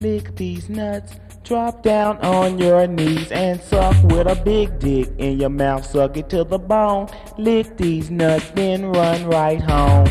Lick These Nuts Cut - Dj Sound.wav